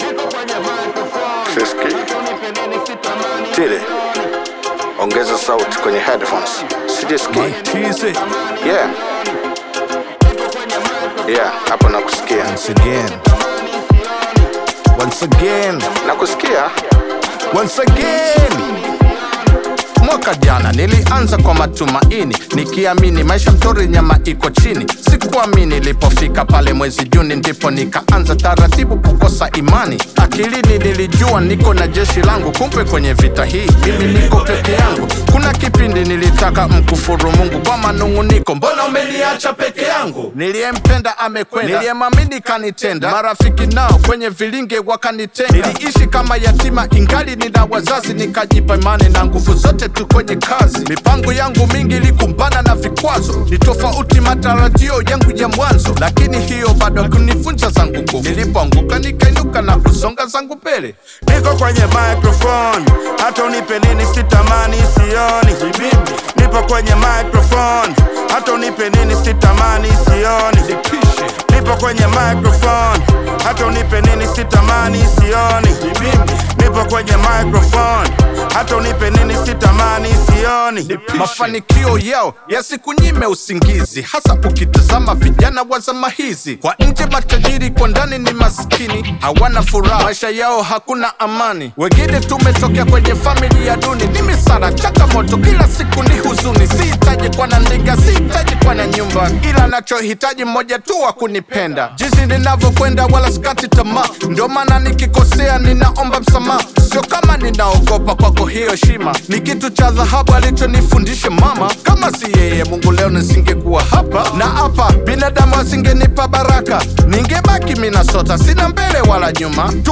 energetic Afro-pop/Bongo Flava single